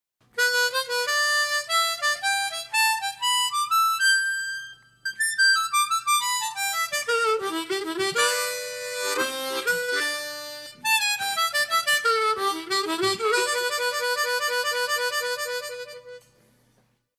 La base musical que encontrarás en la clase es en tonos MI menore y tocaremos una armónica en C tocando en quinta posición.
TÉCNICA 7: RUNNINGS. La última técnica de la que te hablo es el running, cuando se recorre gran parte del instrumento tocando varias notas y enfatizando el aspecto de la velocidad.